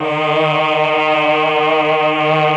RED.CHOR1  9.wav